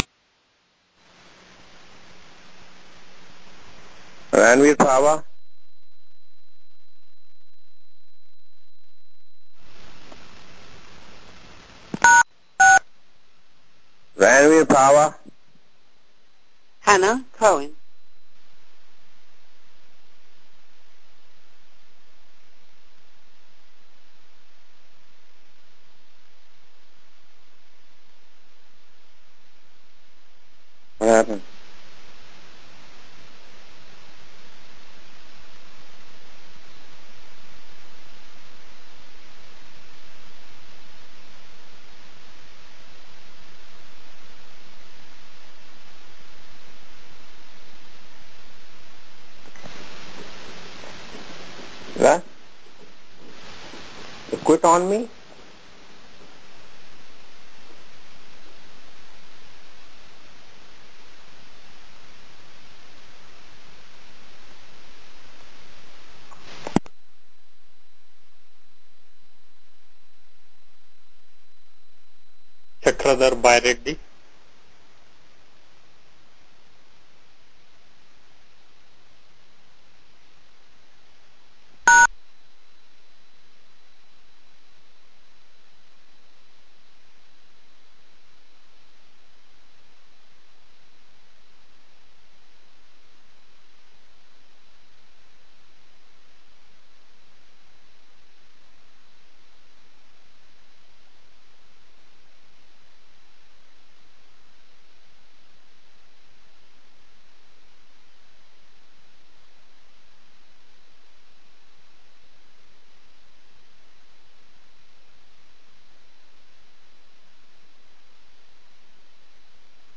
Due to the generous donation of our sponsors, this two hour lecture is free for everyone.